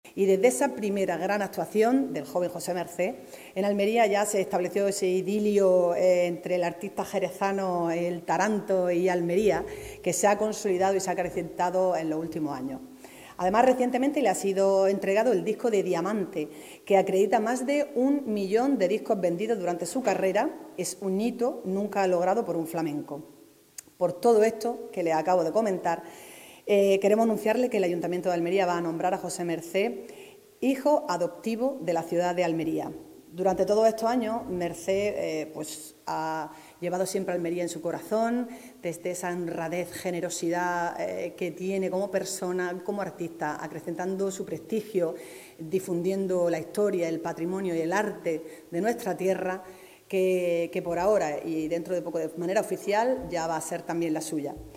ALCALDESA-JOSE-MERCE-HIJO-ADOPTIVO-ALMERIA.mp3